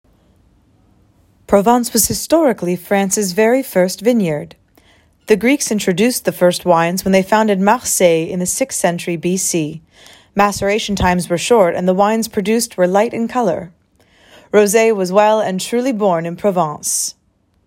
VO Domaine du Vin